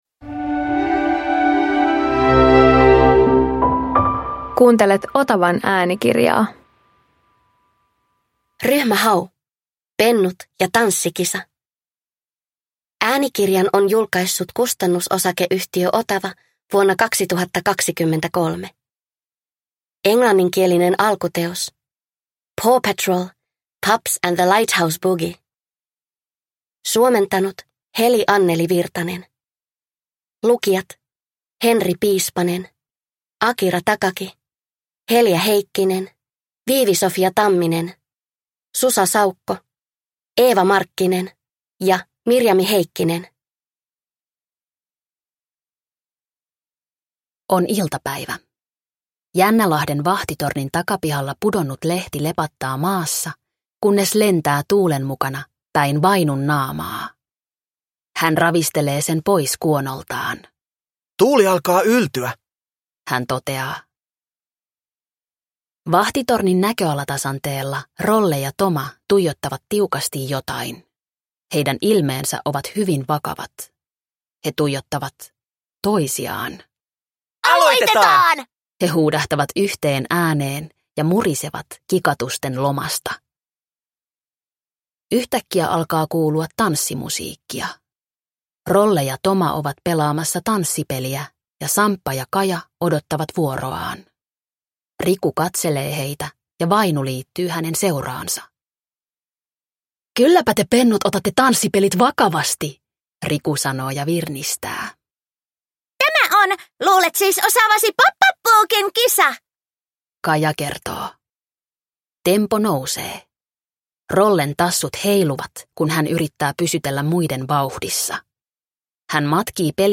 Ryhmä Hau - Pennut ja tanssikisa – Ljudbok